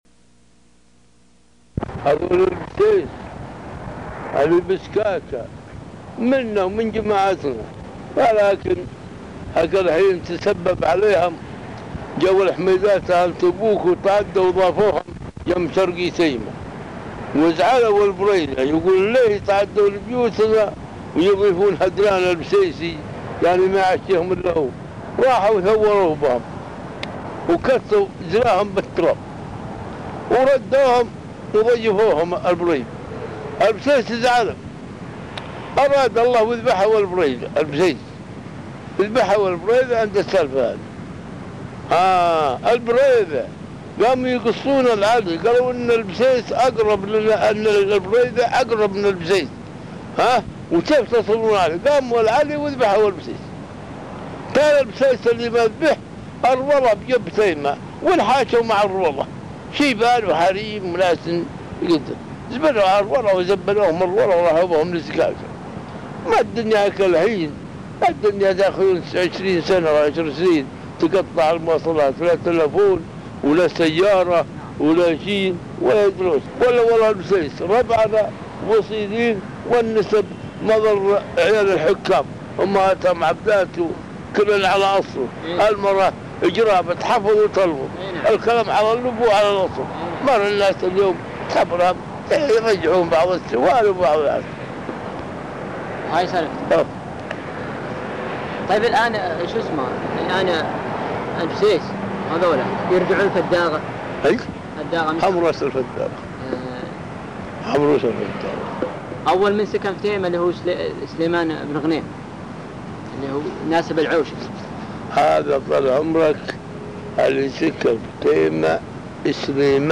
تسجيل صوتي للشيخ